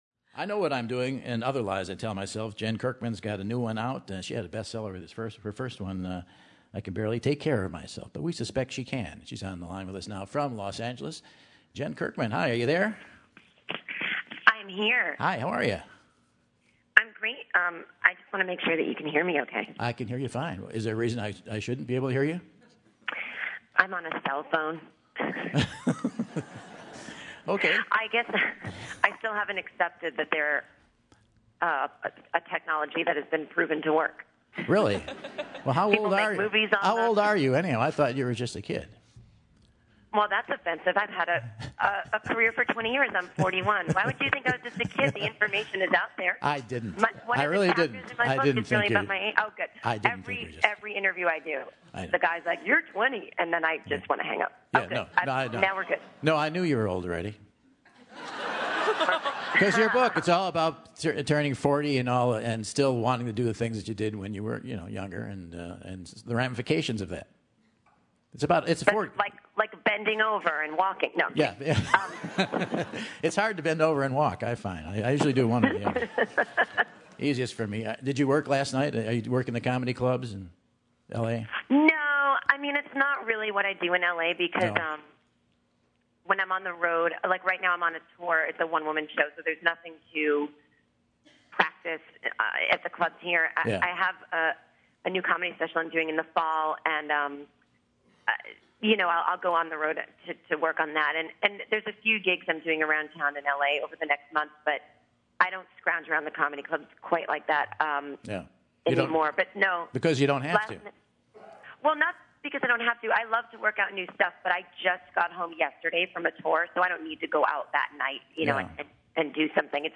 Funny lady from Chelsea Lately and Comedy Central's Drunk History, Jen Kirkman picks up the phone to chat about her latest book, I Know What I'm Doing!